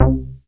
DEEP2 C4.wav